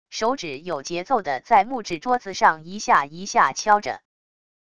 手指有节奏的在木质桌子上一下一下敲着wav音频